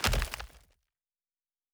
Wood 05.wav